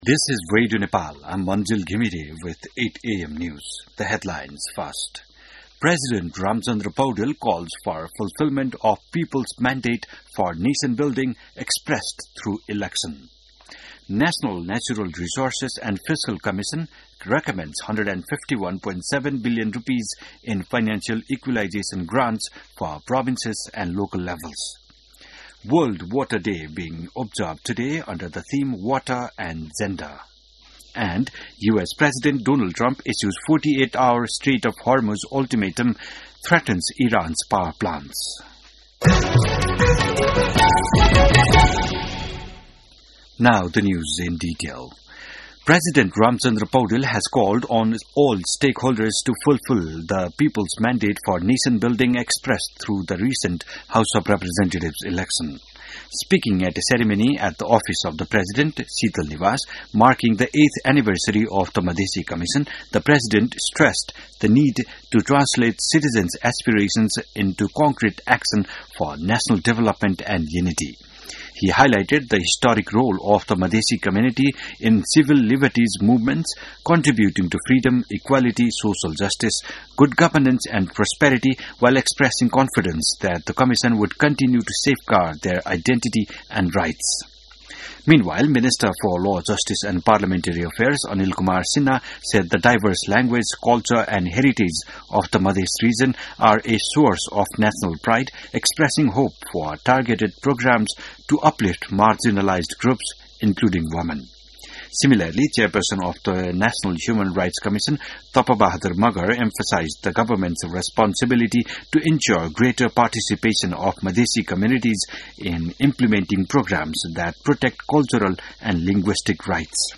बिहान ८ बजेको अङ्ग्रेजी समाचार : ८ चैत , २०८२